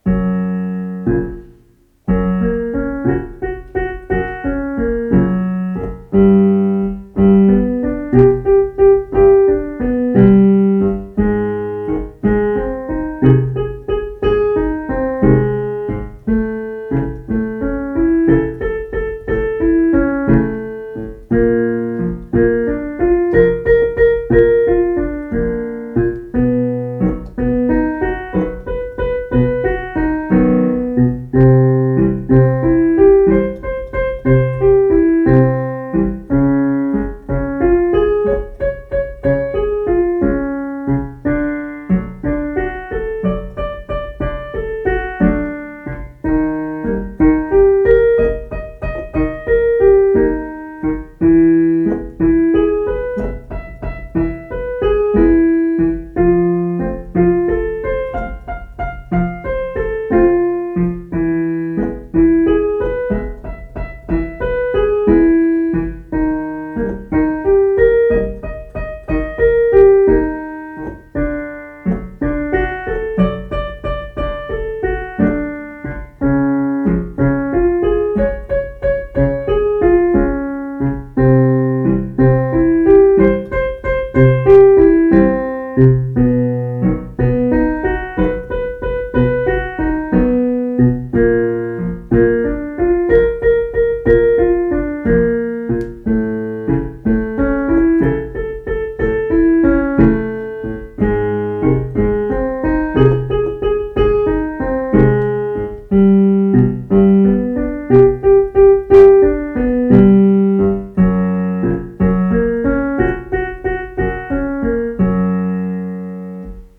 Männerstimme - tiefe Stimmen
Oktaven-Wiederholung_tief.mp3